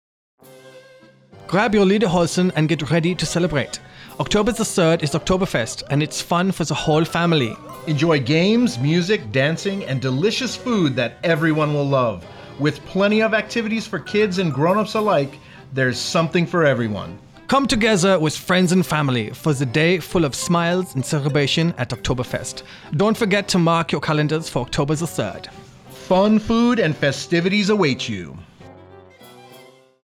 Thirty-second spot highlighting the Oktoberfest to be aired on AFN Bahrain's morning and afternoon radio show.